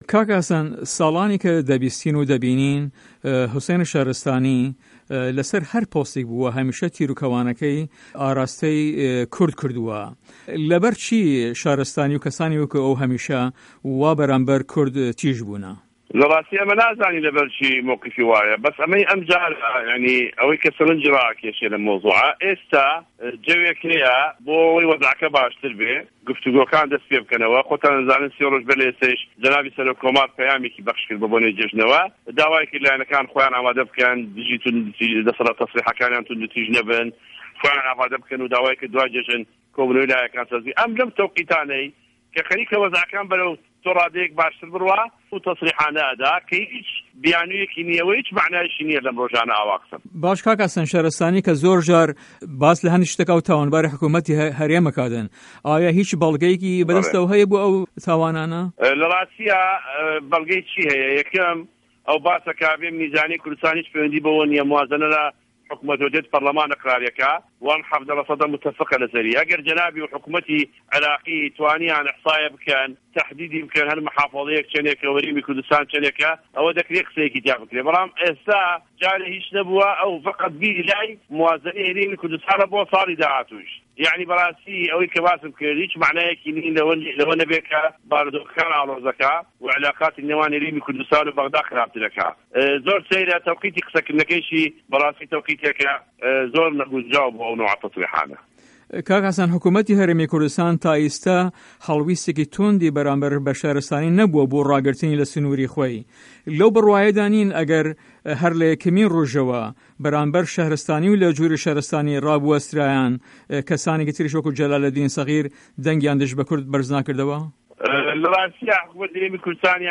وتو وێژ له‌گه‌ڵ حه‌سه‌ن جیهاد